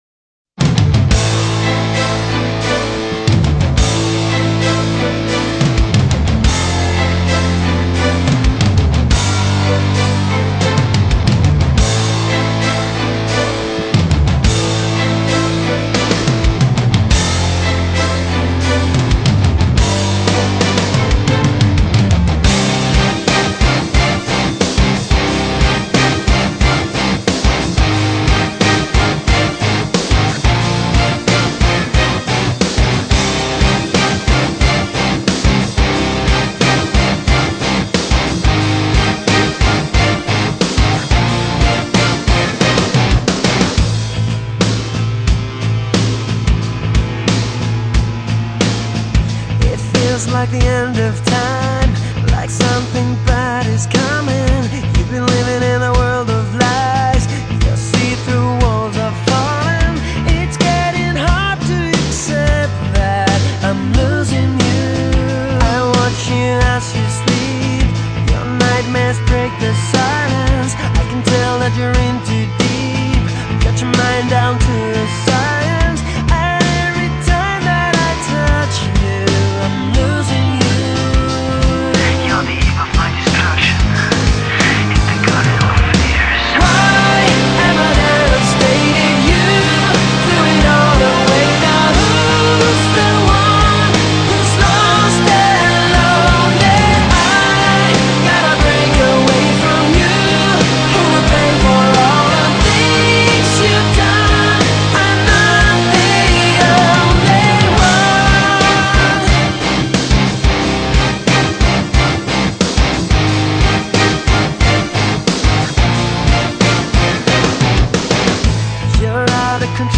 rock музыка